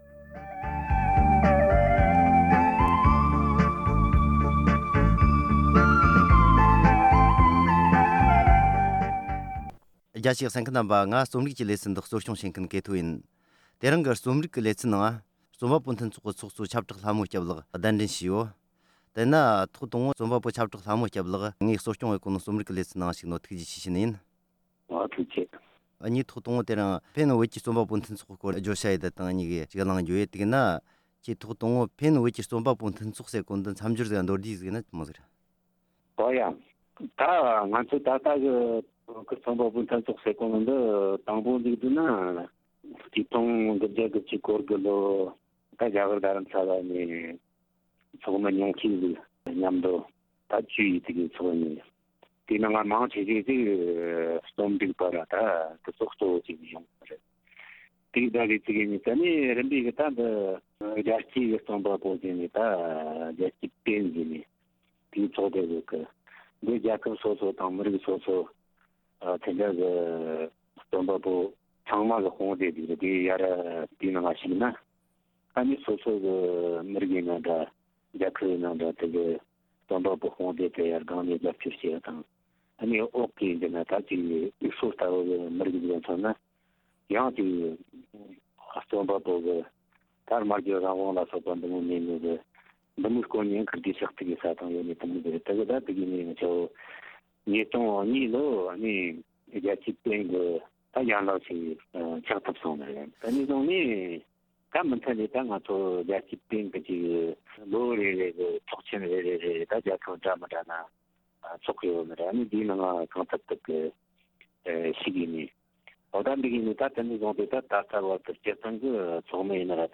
པེན་ཕྱི་སྡོད་བོད་ཀྱི་རྩོམ་པ་པོའི་མཐུན་ཚོགས་ཀྱི་ཚོགས་དུས་ཐེངས་བཞི་པའི་སྐོར་གླེང་མོལ།